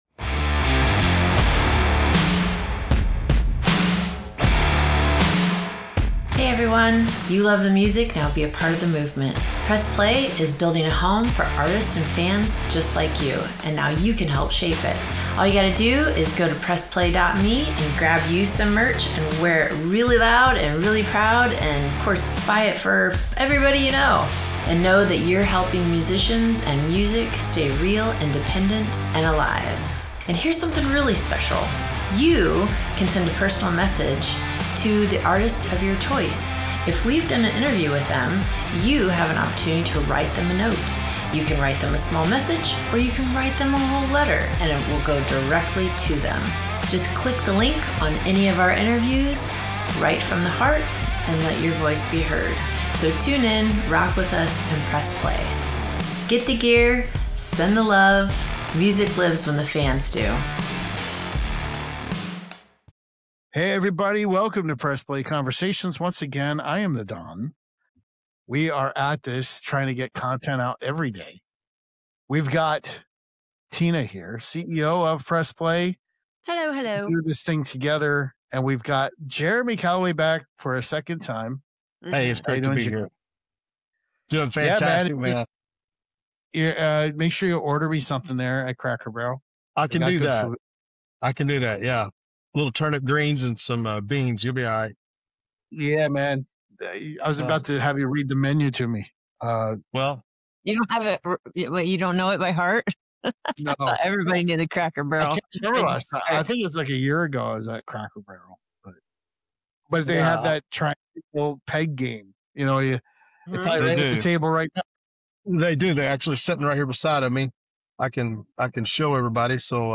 This is what real conversations sound like when the walls come down and the music does the heavy lifting.